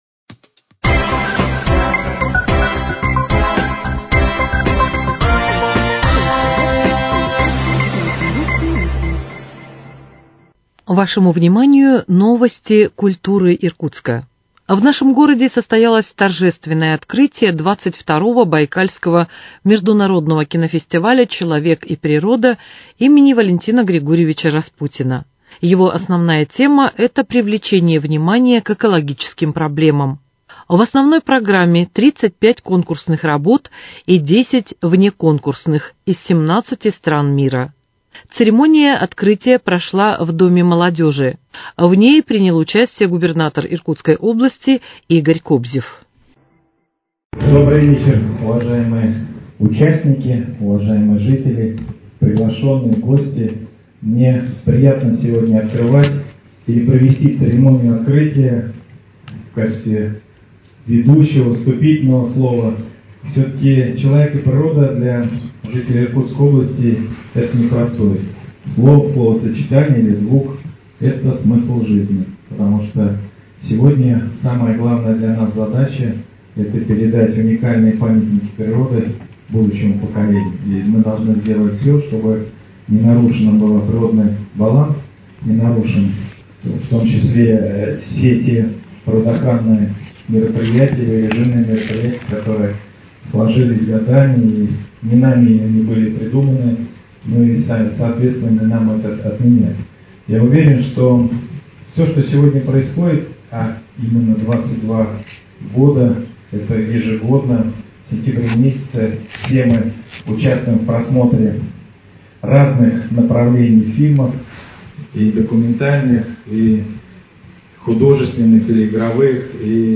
Репортажный материал о проведении в Иркутске 22-го Байкальского Международного кинофестиваля «Человек и природа» им. В.Г.Распутина.